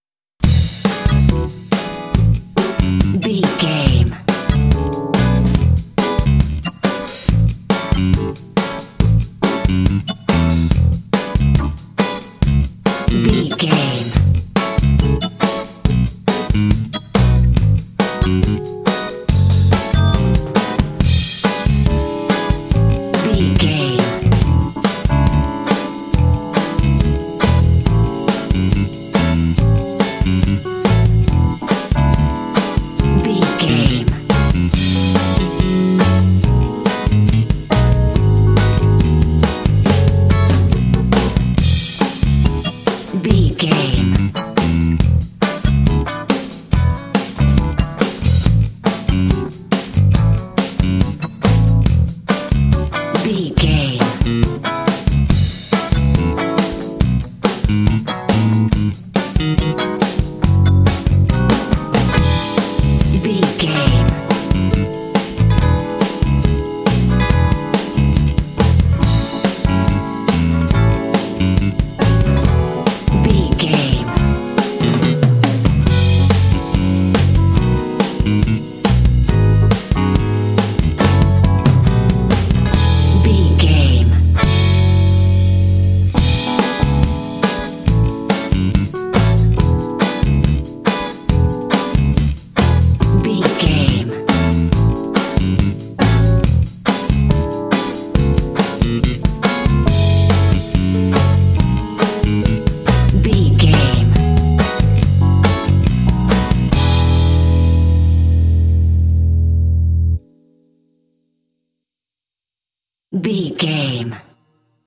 Uplifting
Ionian/Major
funky
groovy
electric guitar
bass guitar
drums
percussion
electric piano
Funk
jazz
blues